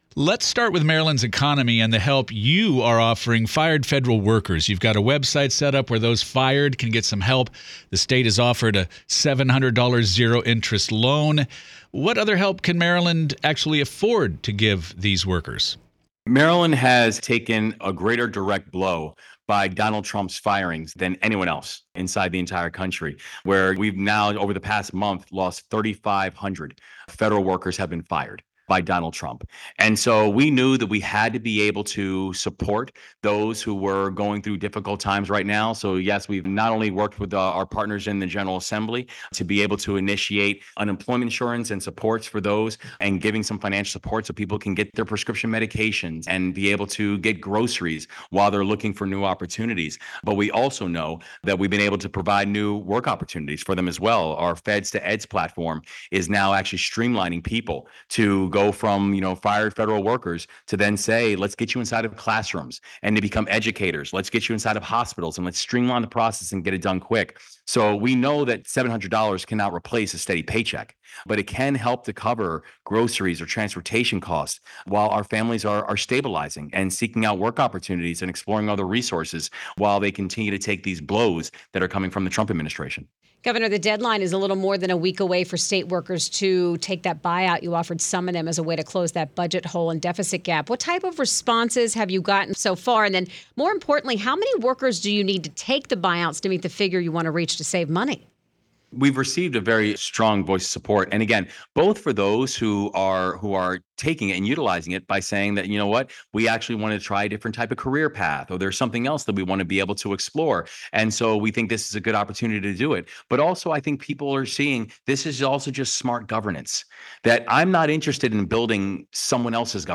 wes-moore-interview.mp3